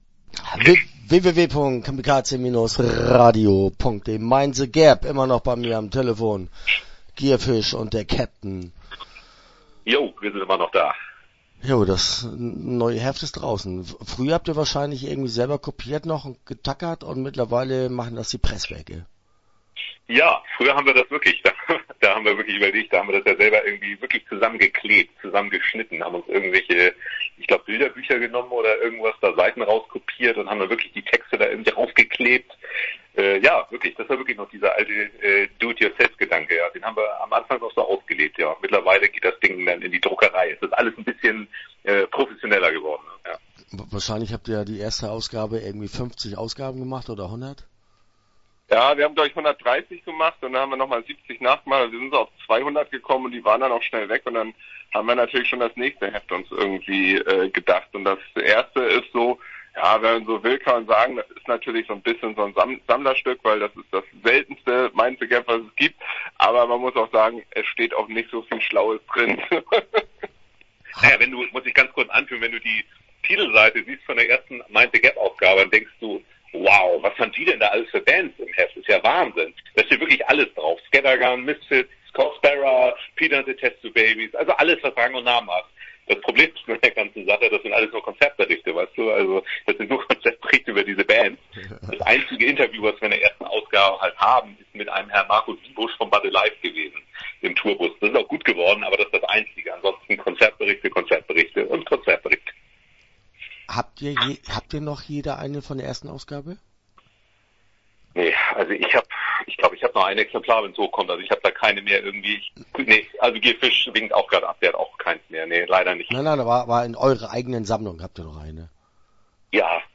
Mind The Gap - Interview Teil 1 (8:48)